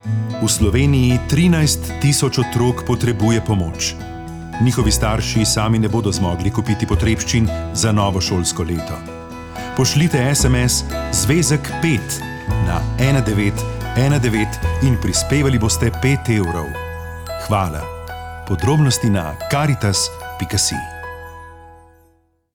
Radijski oglas